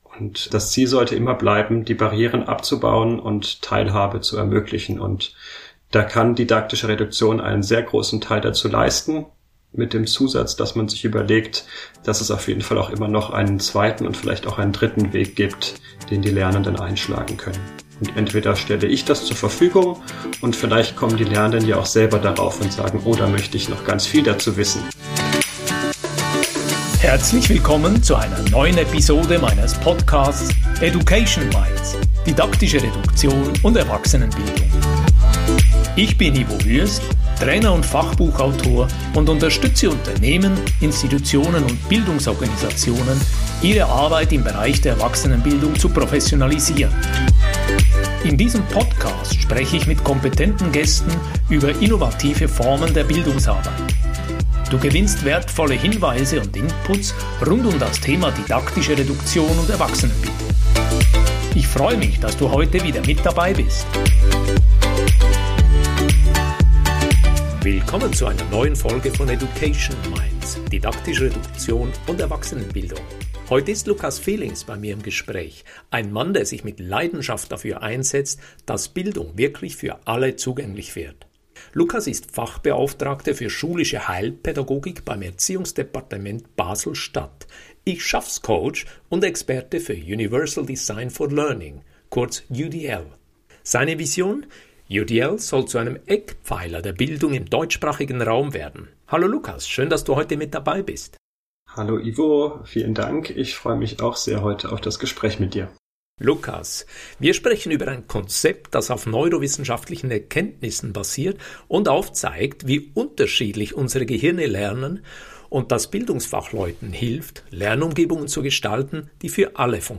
Wir sprechen darüber, warum UDL mehr ist als eine Sammlung guter Methoden, wie neurowissenschaftliche Erkenntnisse den Blick auf Lernen verändern und weshalb Inklusion nicht durch Nachbesserung, sondern durch vorausschauende Gestaltung gelingt. Besonders interessiert mich dabei die Verbindung von UDL und didaktischer Reduktion: Wie lassen sich Inhalte so strukturieren, dass das Wesentliche sichtbar bleibt und gleichzeitig unterschiedliche Zugänge eröffnet werden? Das Gespräch eröffnet Perspektiven für Schule, Weiterbildung und Erwachsenenbildung und macht deutlich, warum UDL im deutschsprachigen Raum deutlich mehr Aufmerksamkeit verdient.